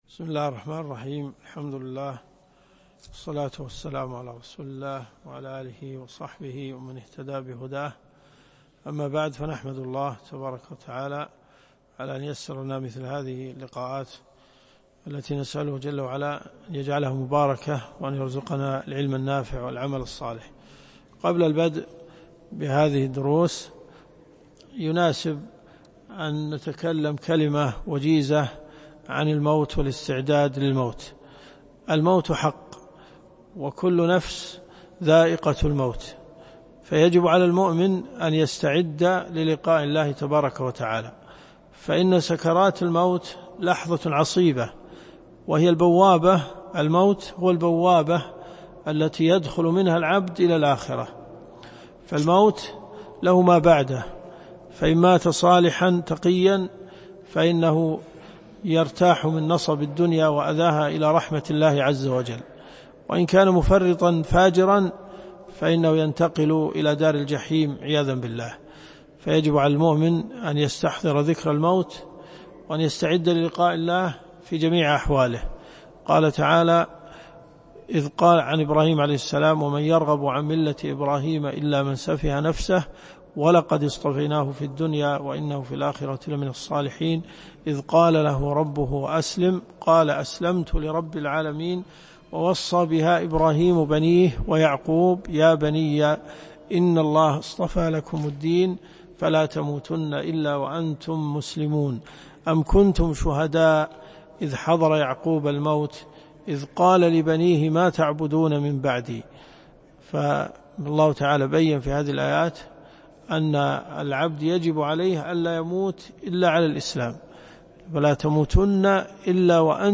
الدرس في الدقيقة 3.10 . شرح في 1433 مأخوذ من مكان آخر ليجبر النقص